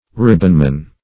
Search Result for " ribbonman" : The Collaborative International Dictionary of English v.0.48: Ribbonman \Rib"bon*man\, n.; pl. -men .